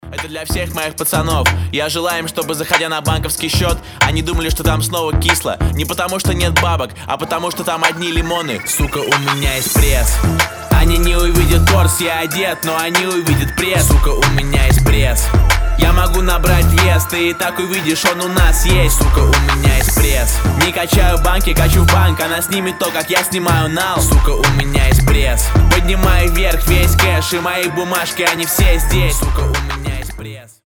• Качество: 320, Stereo
Хип-хоп
русский рэп
качающие
Bass